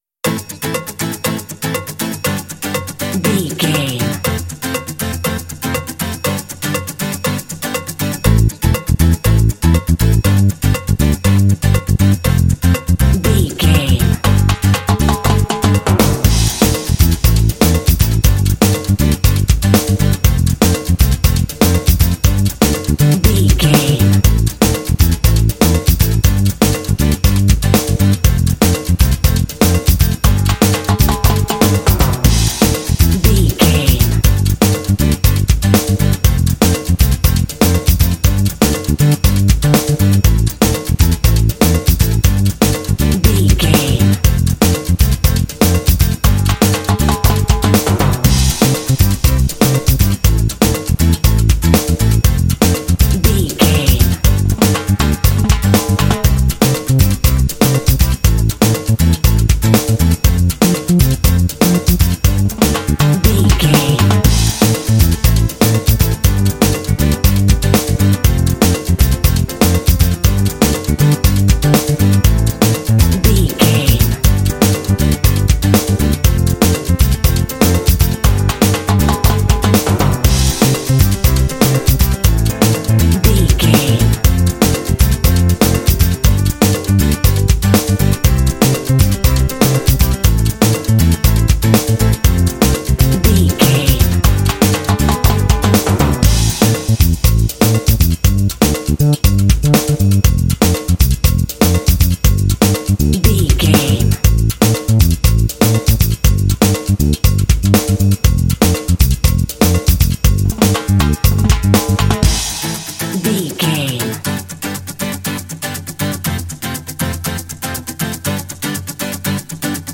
Uplifting
Aeolian/Minor
smooth
lively
driving
percussion
bass guitar
drums
piano
acoustic guitar
latin